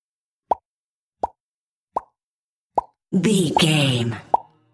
Cartoon pop
Sound Effects
Atonal
funny
cheerful/happy